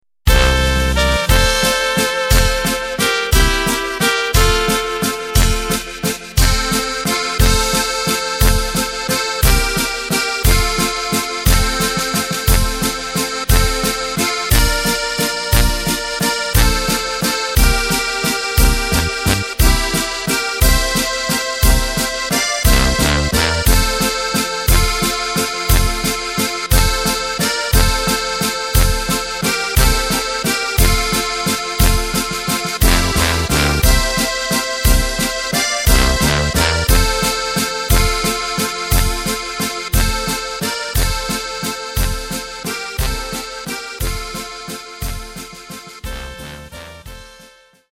Takt:          3/4
Tempo:         177.00
Tonart:            F
Playback mp3 Demo